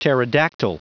Prononciation du mot pterodactyl en anglais (fichier audio)
Prononciation du mot : pterodactyl